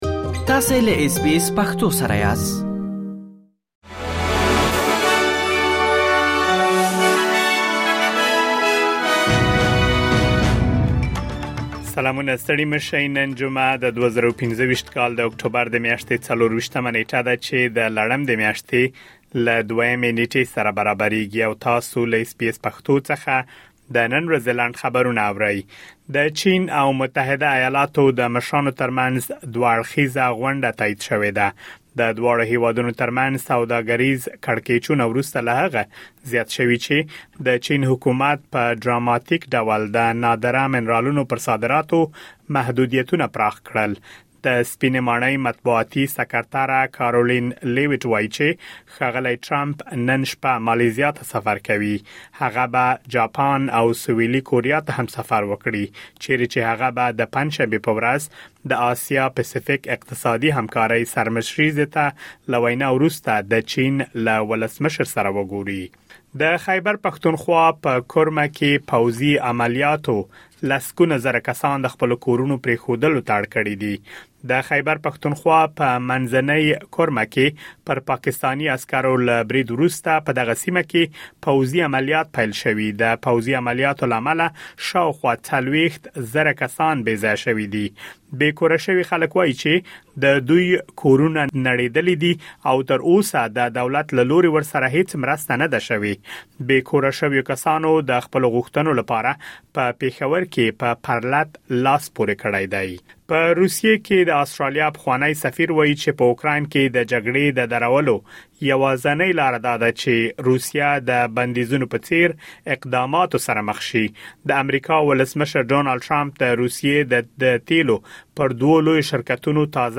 د اس بي اس پښتو د نن ورځې لنډ خبرونه |۲۴ اکټوبر ۲۰۲۵
د اس بي اس پښتو د نن ورځې لنډ خبرونه دلته واورئ.